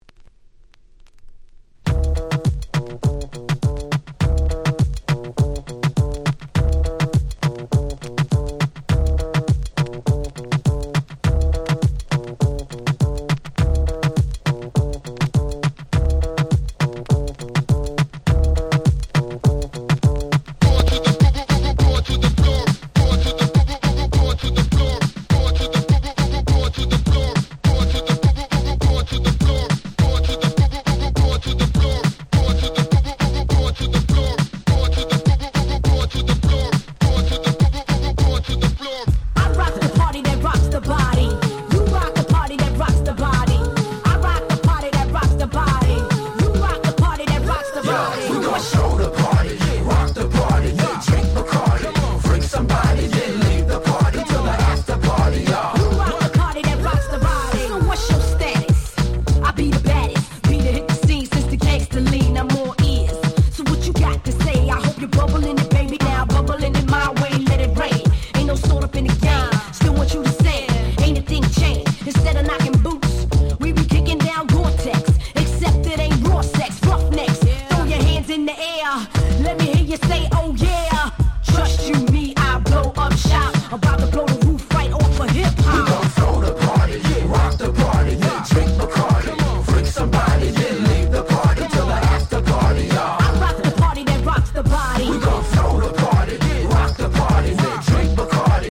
Main Streamヒットを更にフロア仕様にこの盤オンリーとなるRemixを施した使えるシリーズ！！
全6曲全部アゲアゲ！！
勝手にRemix 勝手にリミックス Hip Hop R&B 00's